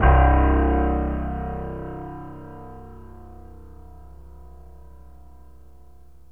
PIANO 0001.wav